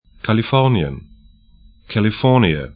Pronunciation
Kalifornien kali'fɔrnĭən California kælɪ'fɔ:niə en Gebiet / region 37°30'N, 119°30'W